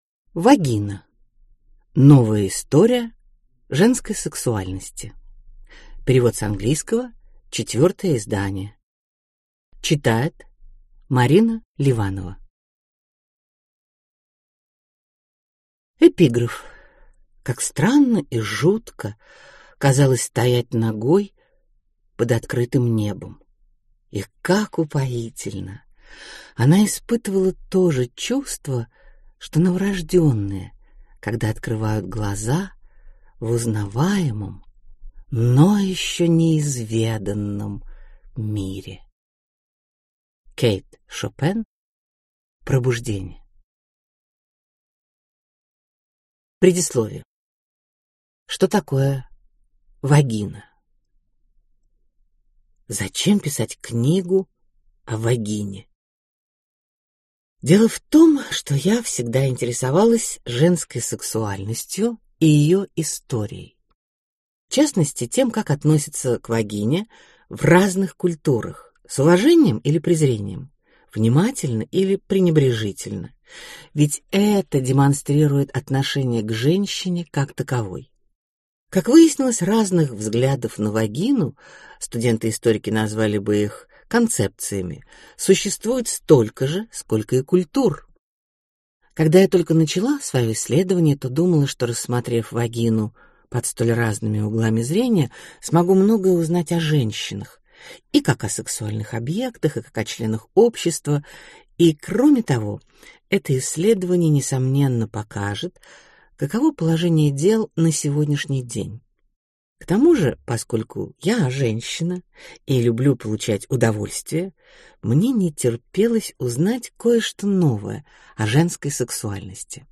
Аудиокнига Вагина: Новая история женской сексуальности | Библиотека аудиокниг